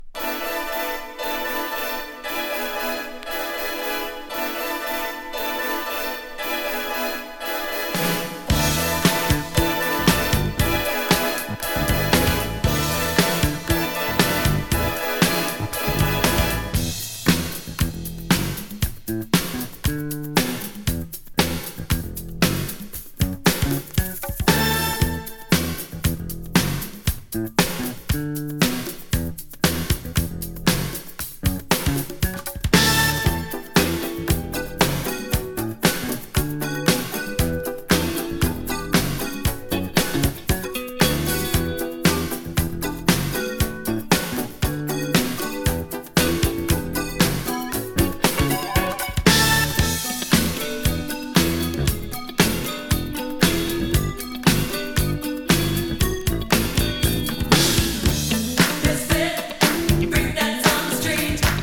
ソウル、ジャズっぽい質感アリのUKポップロックバンド
ファンカラティーナぽさも有ります。